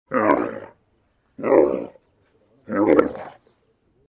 Голос и звуки верблюда